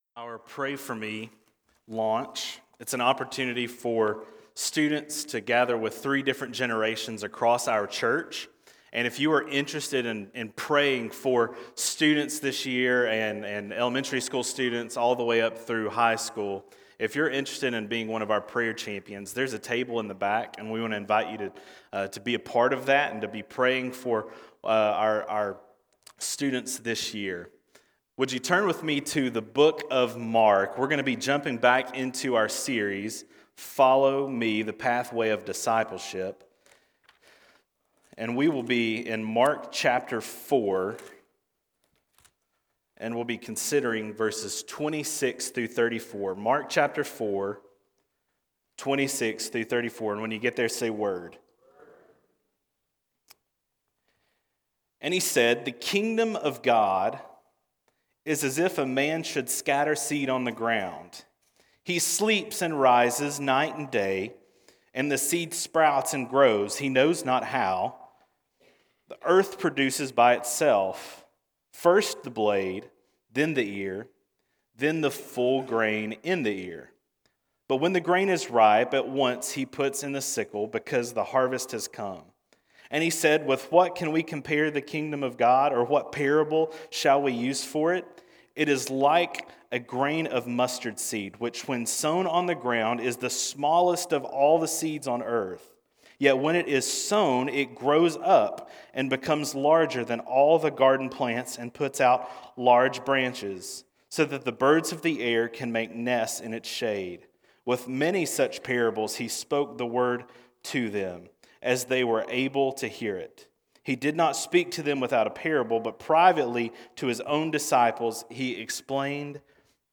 Sermons › Mark 4:26-34 – Kingdom Come